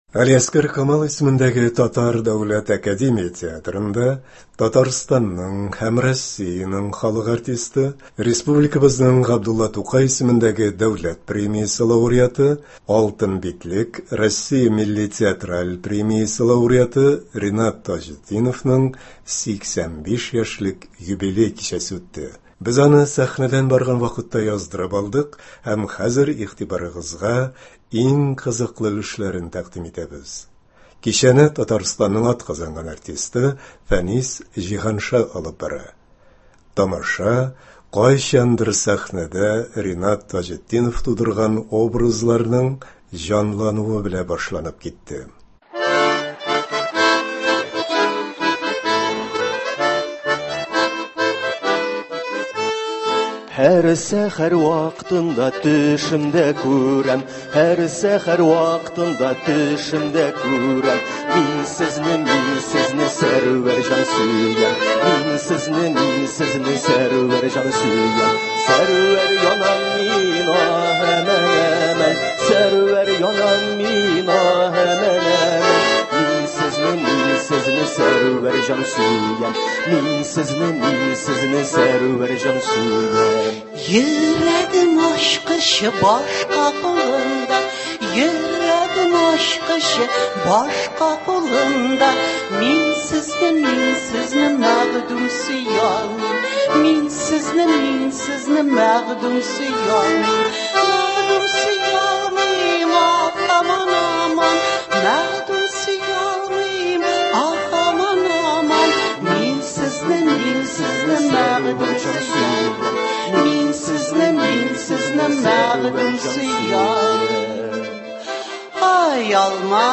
Г.Камал исемендәге Татар Дәүләт академия театрында Татарстанның һәм Россиянең халык артисты, Республикабызның Г.Тукай исемендәге Дәүләт премиясе лауреаты, “Алтын битлек” Россия милли театраль премиясе лауреаты Ринат Таҗетдиновның 85 яшьлек юбилей кичәсе үтте. Без аны сәхнәдән барган вакытта яздырып алдык һәм хәзер игътибарыгызга иң кызыклы өлешләрен тәкъдим итәбез.